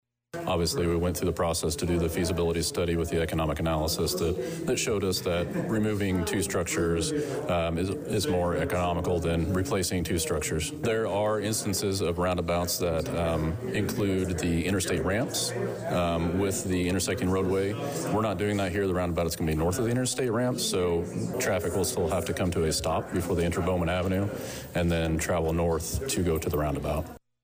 A consistently busy late Tuesday (Oct 22nd) afternoon meeting at the Church of God on South Bowman in Danville allowed residents to question Illinois Department of Transportation representatives about the planned removal of the Perrysville Road bridge over I-74, and the upgrading of the current Texas Avenue underpass below Bowman into a new roundabout intersection.